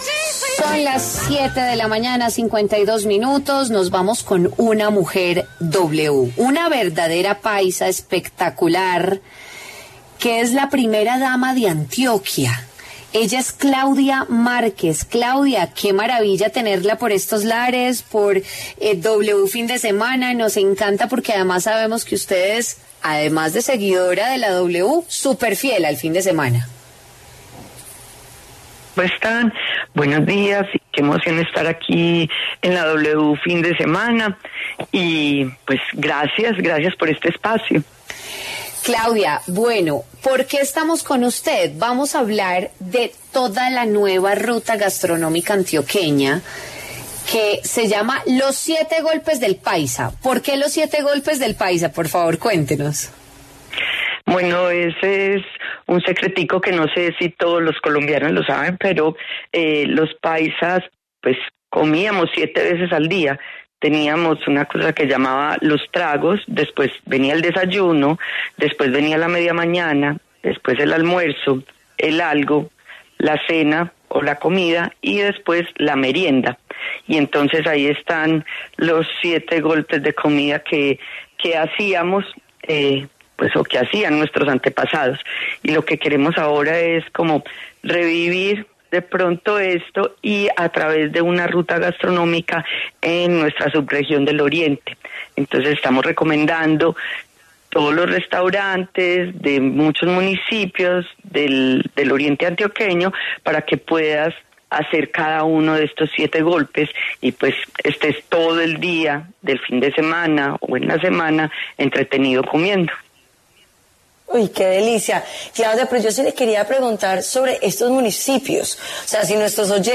estuvo en W Fin De Semana para hablar sobre ‘Los siete golpes del paisa’, la nueva ruta gastronómica en ese departamento.